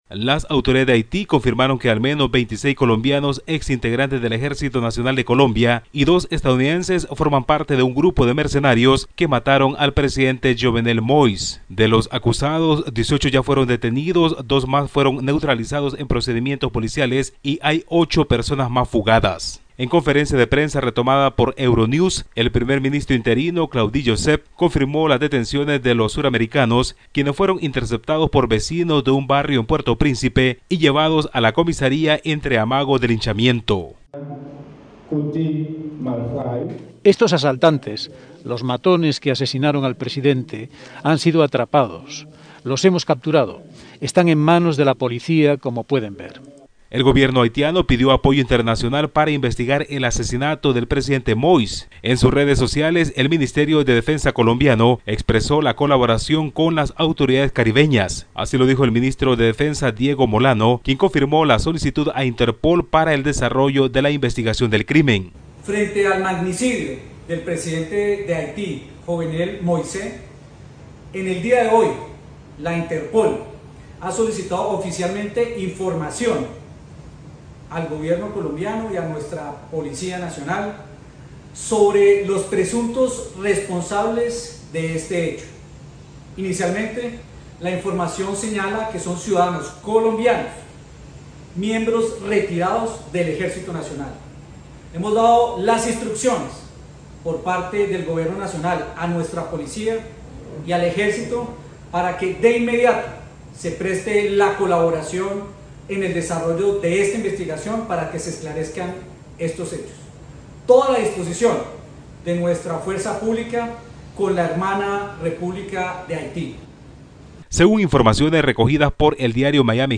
Con el informe nuestro corresponsal en Centroamérica